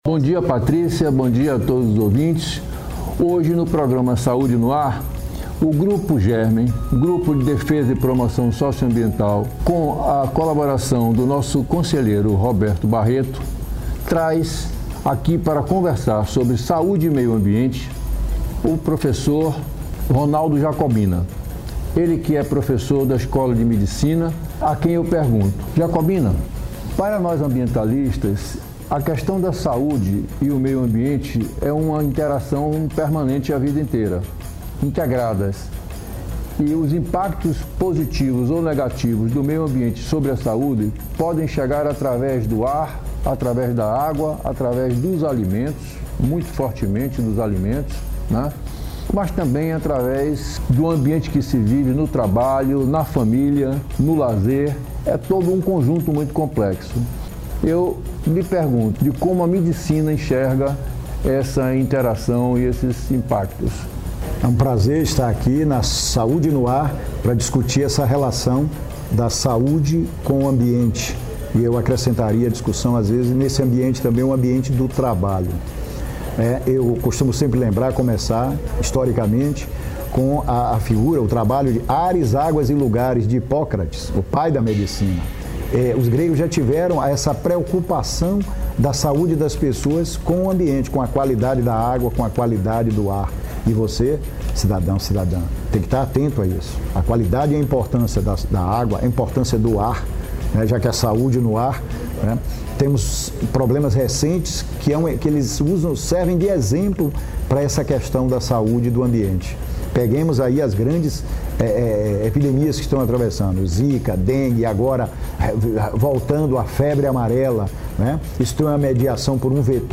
ouvindo o comentário completo do professor